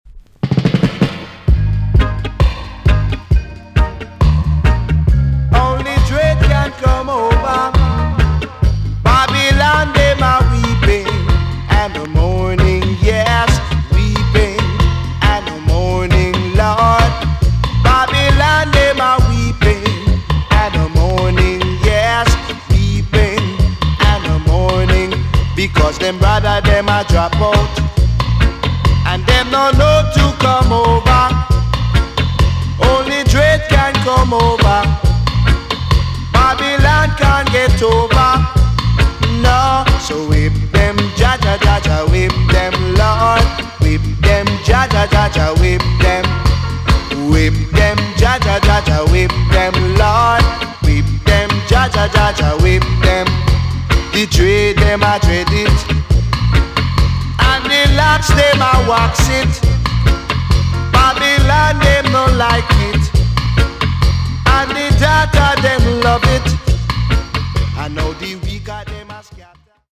TOP >REGGAE & ROOTS
EX- 音はキレイです。
WICKED ROOTS FOUNDATION TUNE!!